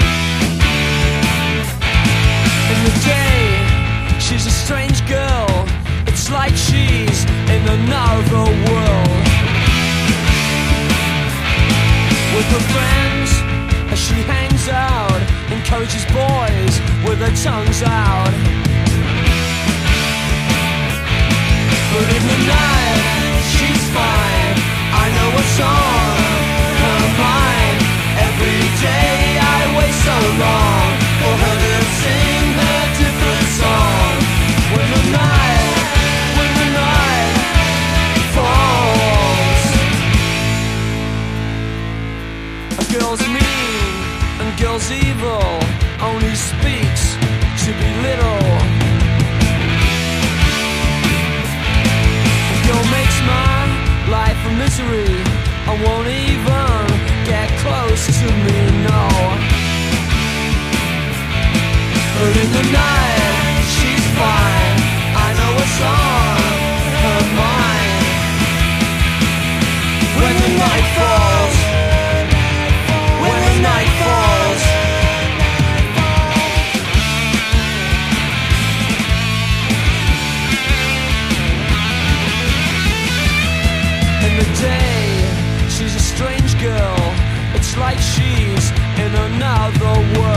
な、みずみずしい泣きじゃくりメロが炸裂する90’Sインディ・ポップ/パワーポップ・クラシック！